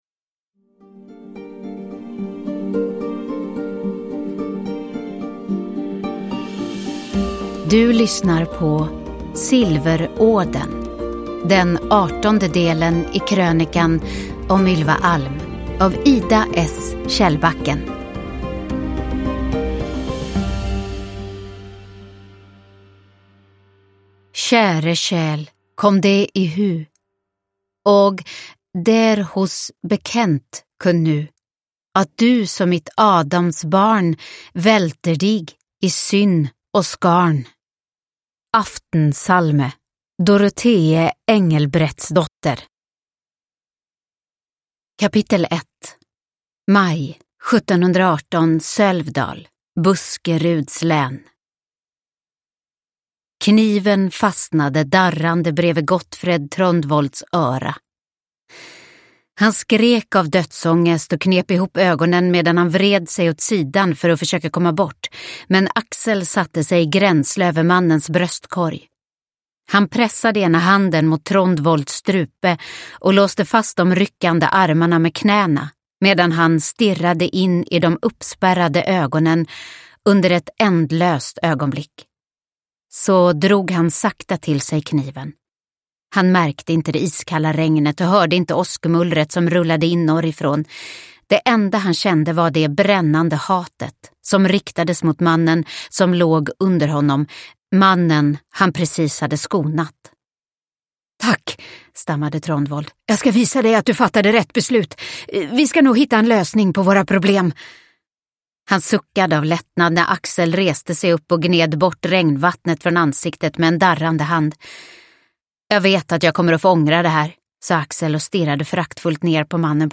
Silverådern – Ljudbok – Laddas ner